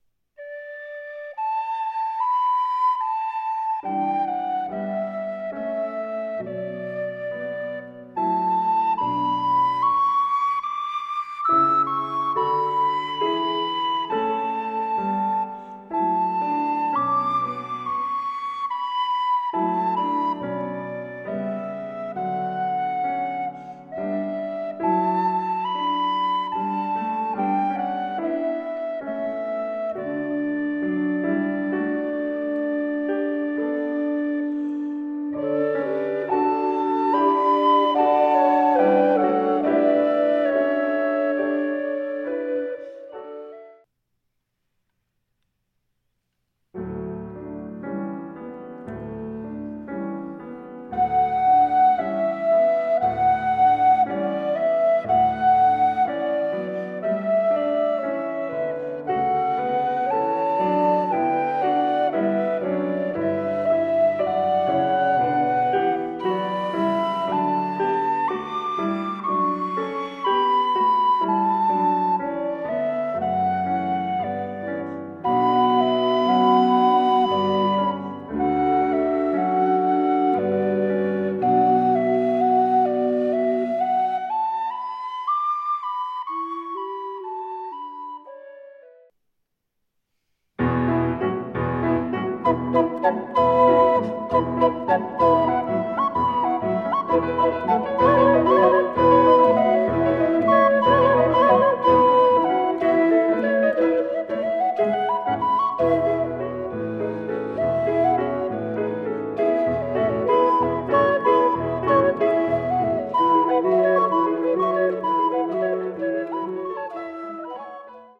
SATB + piano
op zelfgemaakte bambfluiten